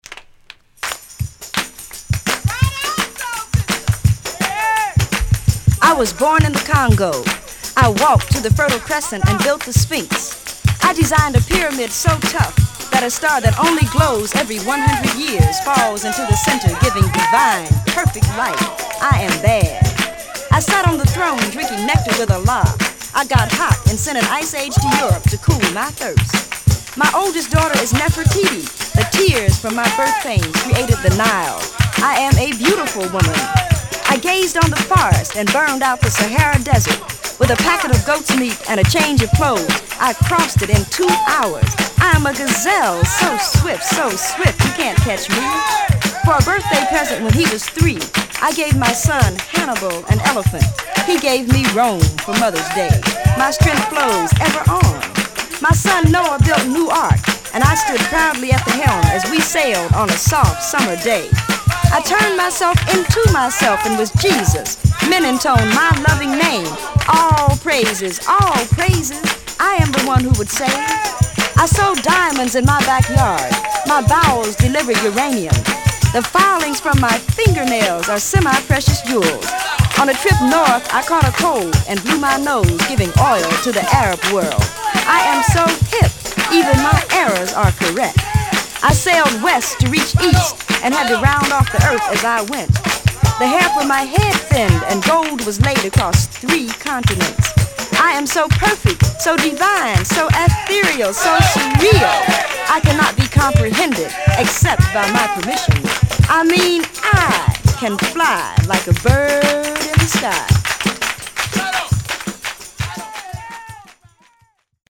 the latter is a beautiful, joyful, raucous